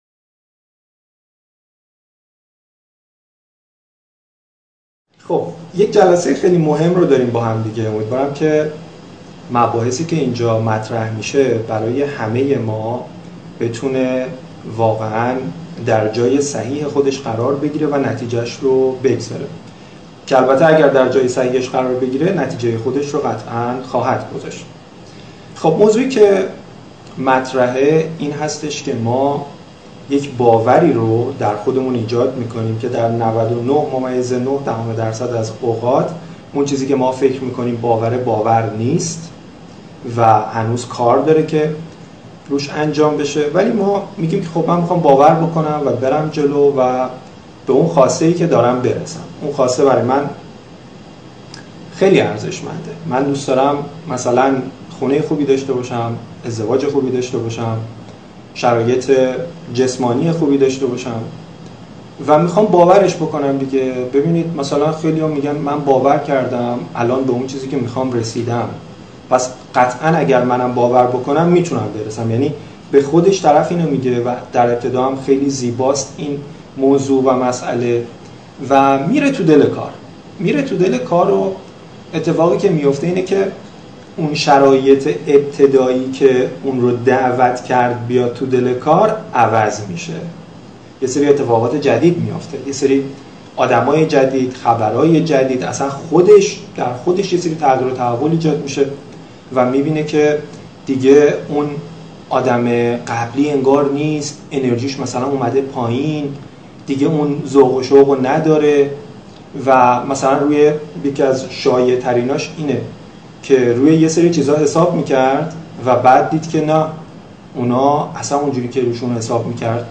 چطور در شرایط سخت باور خود را حفظ کنیم؟( جلسه لایو شماره ۲ ) | توافق خود با " خودآ " موفقیت
فایل تصویری جلسه لایو در اینستاگرام